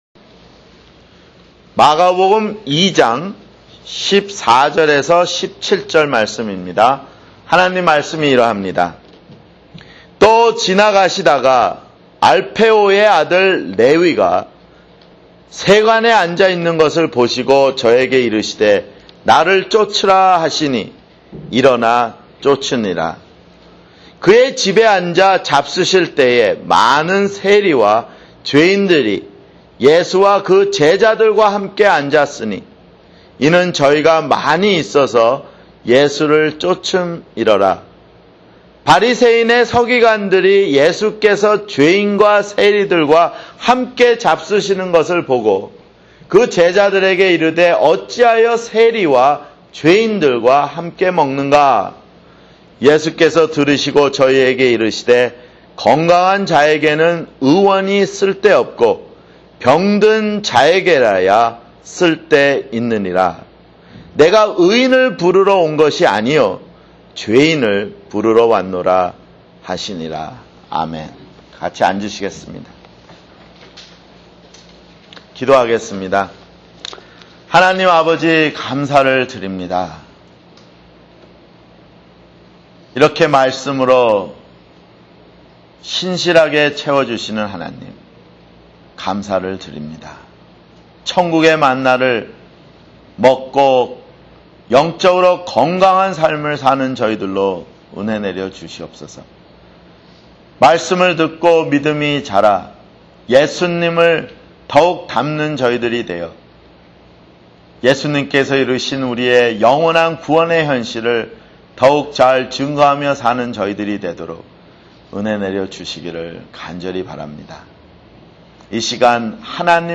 [주일설교] 마가복음 2:14-17